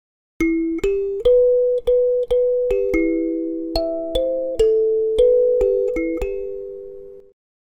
Vibrating Metal (00:07)
Vibrating Metal.mp3